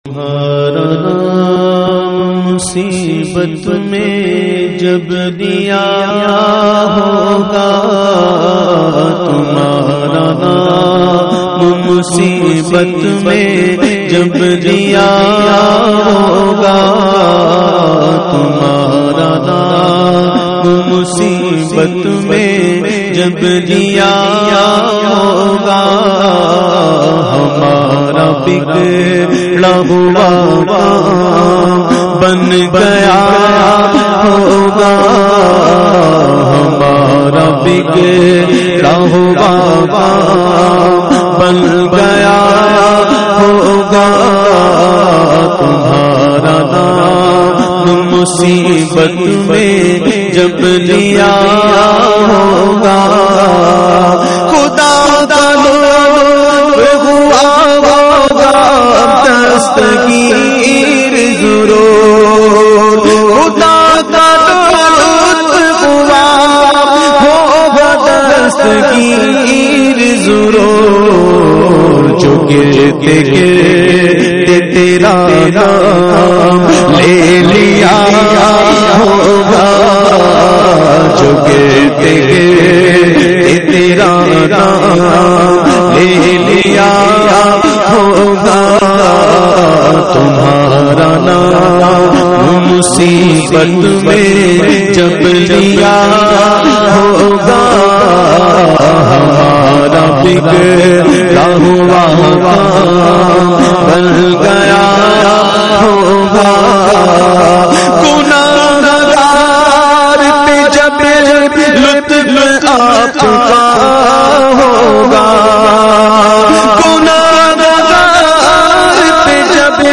The Naat Sharif Tumhara Naam Musibat Mein Jab Liya Hoga recited by famous Naat Khawan of Pakistan Owais Qadri.